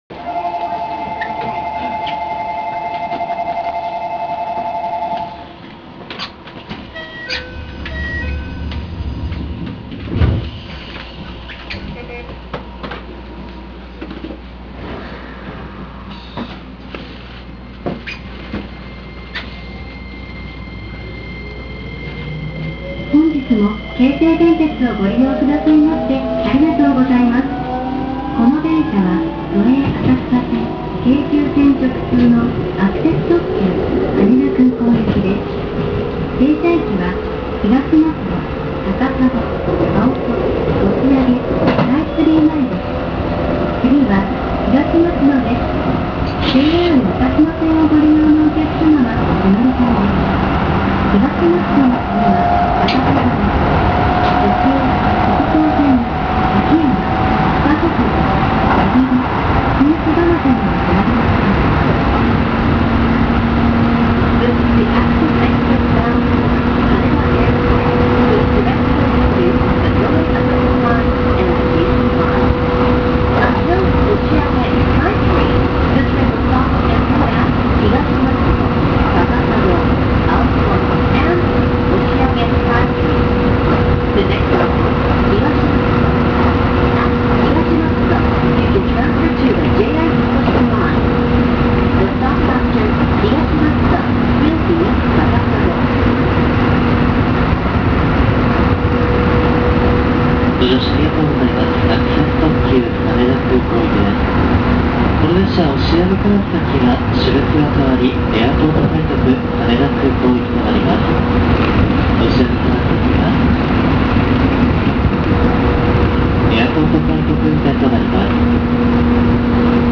・3000形走行音
【成田ｽｶｲｱｸｾｽ】新鎌ヶ谷〜東松戸（4分13秒：1.34MB）
言ってしまえば、東洋IGBTの非常にありふれた音です。
モーター音の転調は１回しか起こりません。
稀に成田ｽｶｲｱｸｾｽの運用に用いられることがありますが、音だけ切り取ってしまうと3050形と全く同じなのであまり面白味は無いかも…。